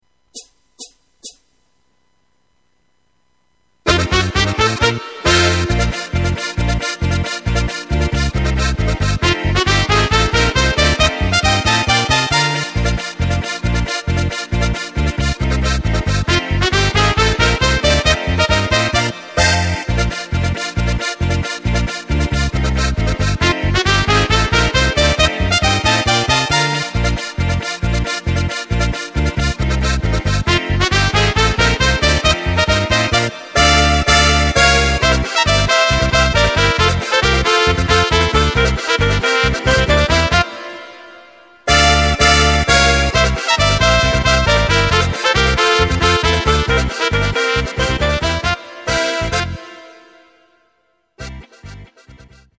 Takt:          2/4
Tempo:         136.00
Tonart:            Bb
Polka für Bariton Solo!